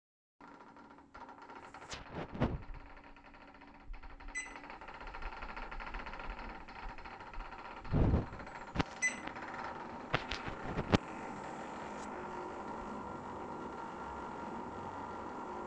Laufband macht komische Geräusche
Das ist ein MERACH MR-T25 Laufband, frisch aus der Packung. Hab das Deck mit Silikonöl eingeölt, bin 200m gelaufen, hab dann das Gerät ausgeschaltet und den Gurt justiert, eine halbe Drehung :S Jetzt macht das Gerät diese Geräusche wenn ich es einschalte (ohne das jemand draufsteht) Anfang der Audiodatei 1km / Ende 2km die Stunde.
Rattert iwas?